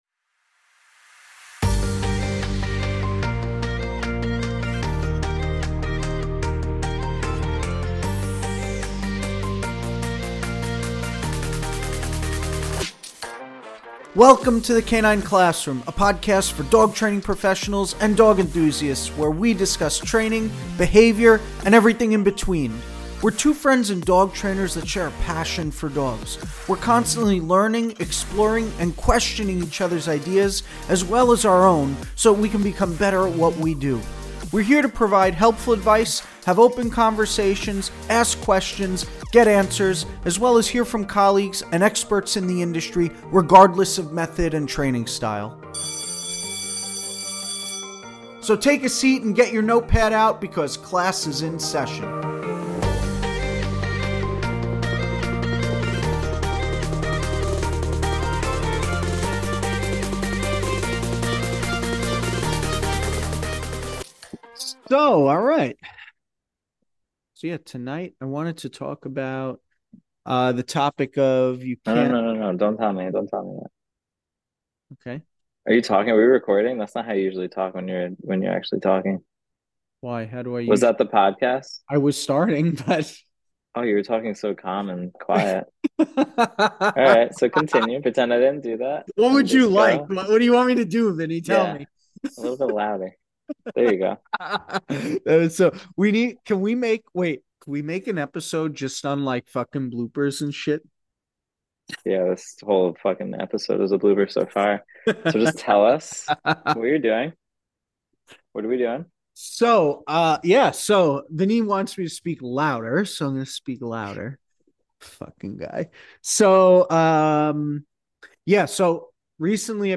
We are two friends and dog trainers who share a passion for dogs.